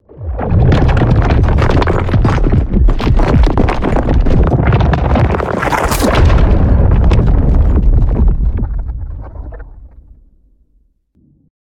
anomaly_mincer_blowout.ogg